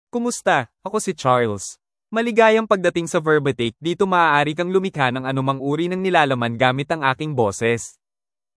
MaleFilipino (Philippines)
Charles is a male AI voice for Filipino (Philippines).
Voice sample
Listen to Charles's male Filipino voice.
Charles delivers clear pronunciation with authentic Philippines Filipino intonation, making your content sound professionally produced.